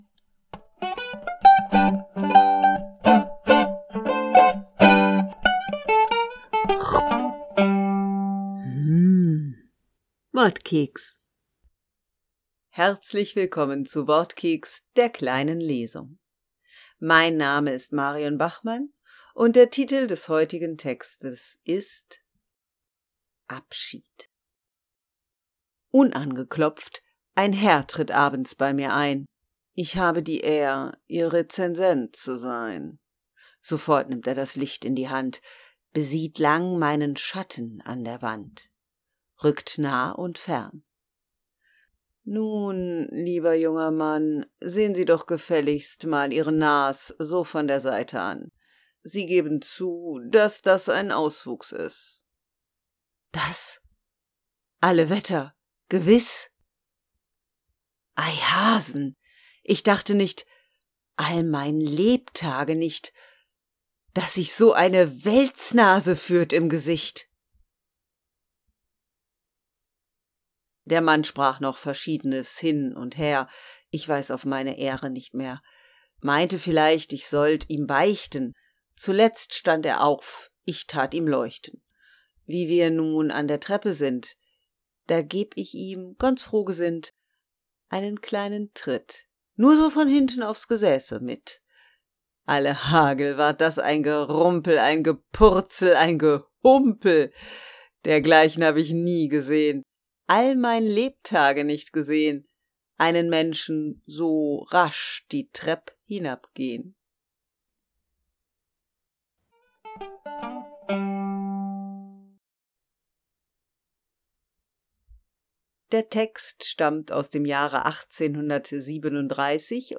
Die kleine Lesung heute mit einem Dichter und seinem Rezensenten.